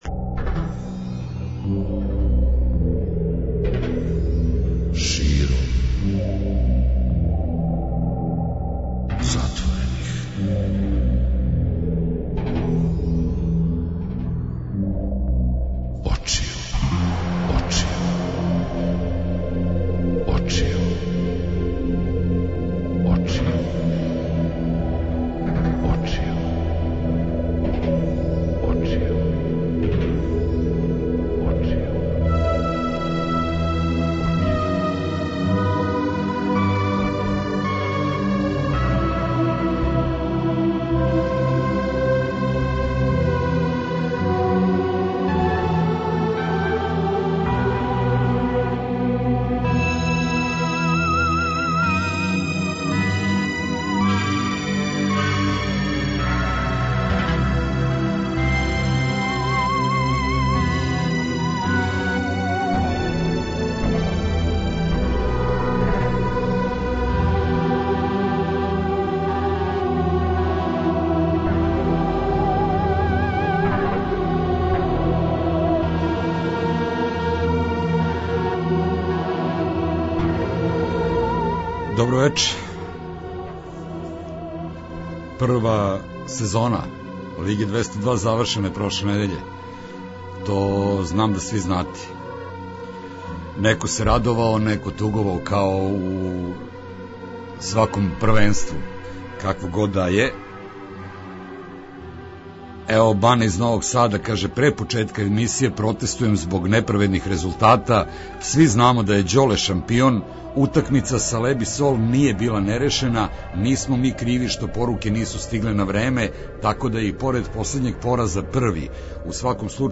Лига 202 и Куп 202 - спој добре рок музике, спортског узбуђења и навијачких страсти.